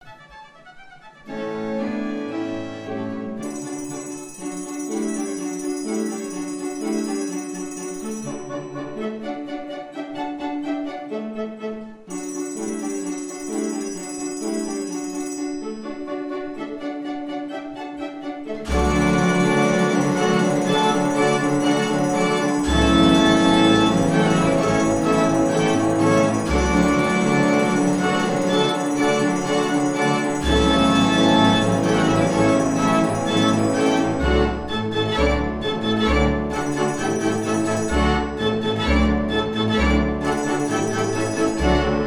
PHILLIPS MONSTRE PAGANINI, MODEL 12 ORCHESTRION